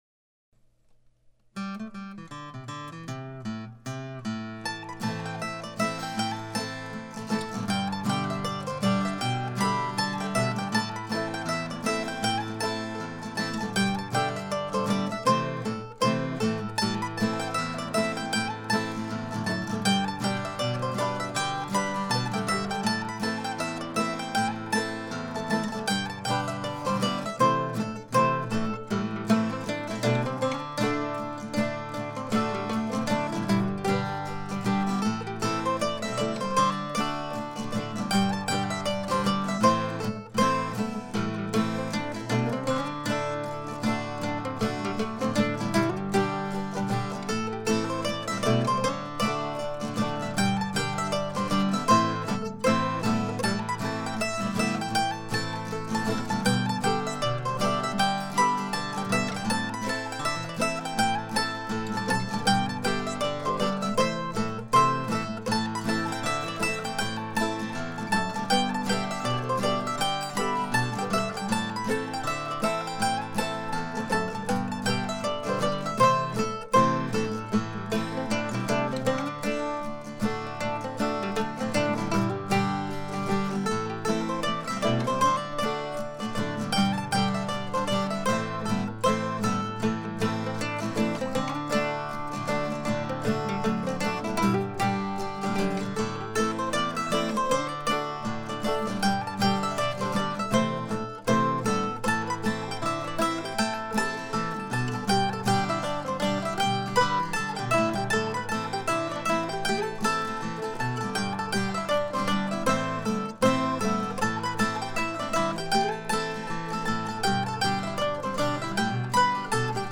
I recorded the tune on a new Tascam DP-008 recorder using the built in mics. Instruments were an old Gibson oval A mandolin and a recent Martin OO-15M guitar. The tempo is comfortable but the tune is also fun played at a dance tempo.